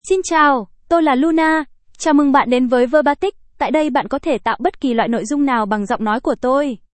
Luna — Female Vietnamese (Vietnam) AI Voice | TTS, Voice Cloning & Video | Verbatik AI
LunaFemale Vietnamese AI voice
Luna is a female AI voice for Vietnamese (Vietnam).
Voice sample
Listen to Luna's female Vietnamese voice.
Luna delivers clear pronunciation with authentic Vietnam Vietnamese intonation, making your content sound professionally produced.